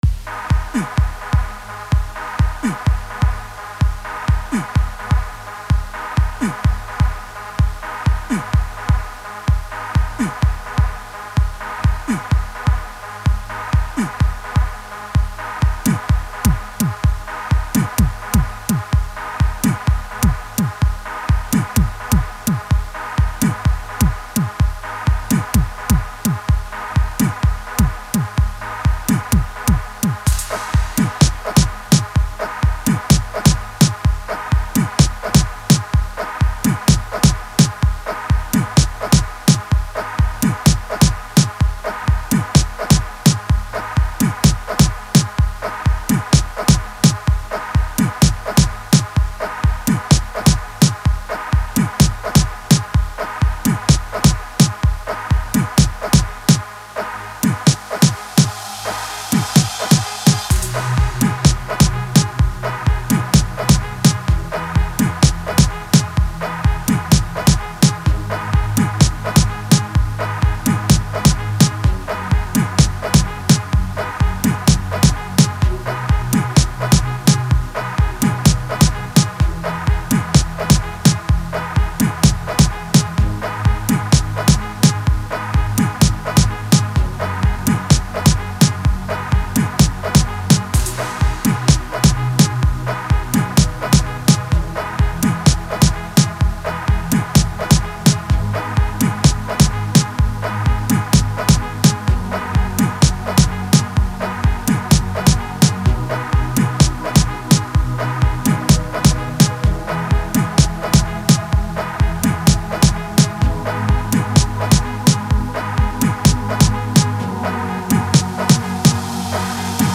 05:36 Genre : Gqom Size